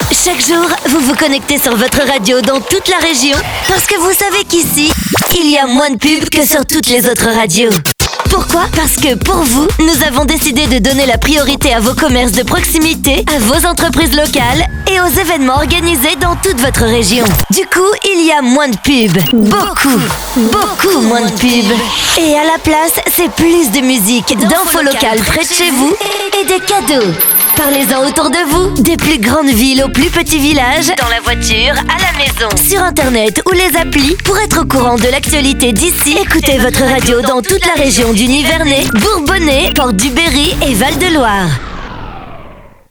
Voix-off pour habillage radio et DJ